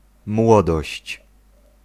Ääntäminen
IPA: [ʒœ.nɛs]